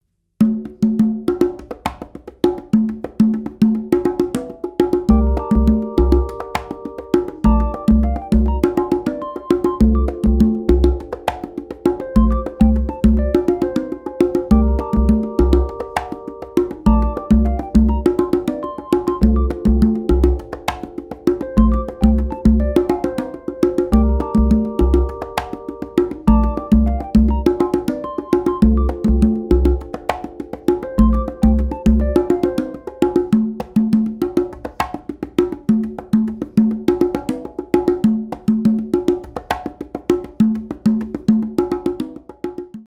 MEINL Percussion Fibercraft Series Tumba 12" - Black (FCR1212BK)